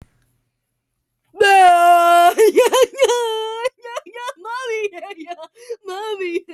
Play and download HELP ME MOMMYY sound effect.
voice record soundboard